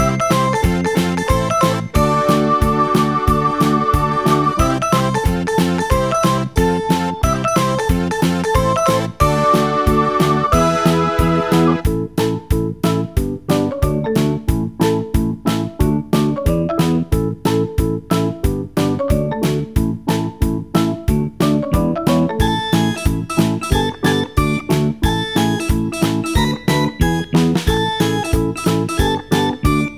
# Children's Music